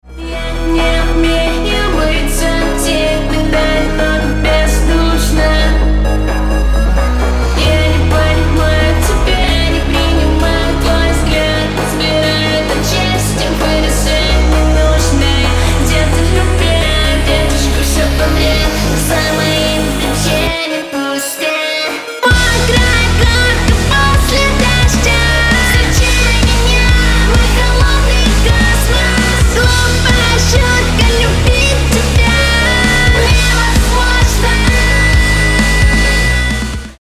Драйвовый рок рингтон